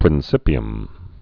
(prĭn-sĭpē-əm)